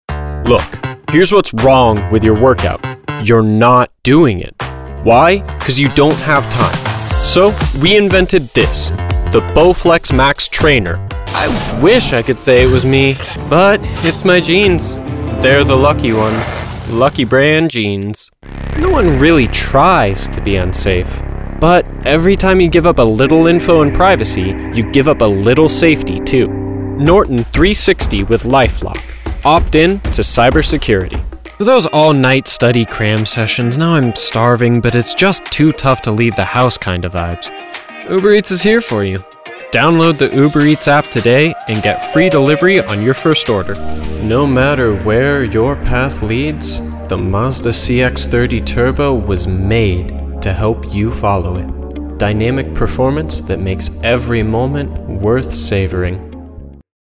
A voice that's Trustworthy, energetic, and down to earth
Commercial Demo
Teen
Young Adult